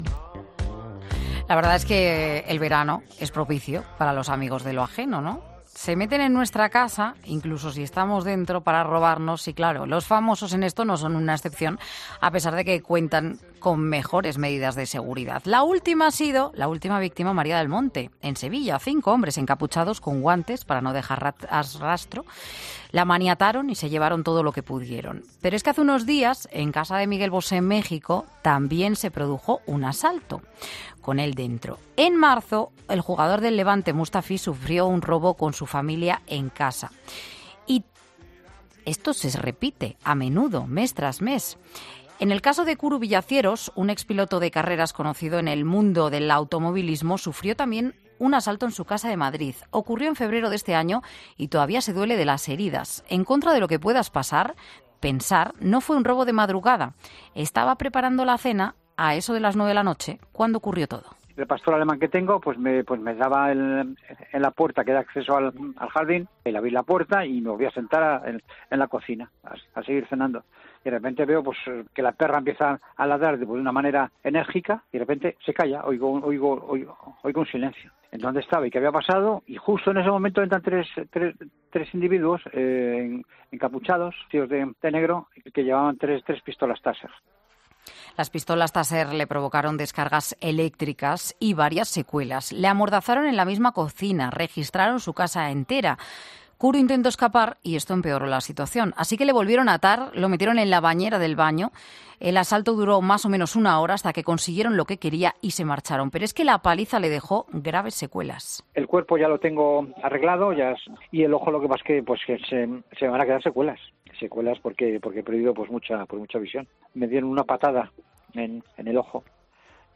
Un policía detalla los cinco pasos a seguir para evitar sufrir un robo en tu casa: "Solemos hacerlo"